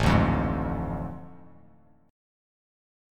AM7sus4#5 chord